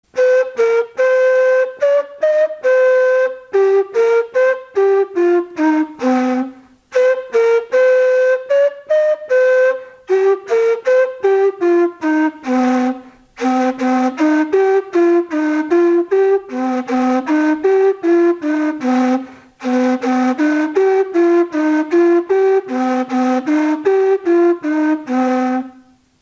Традиционный звук курая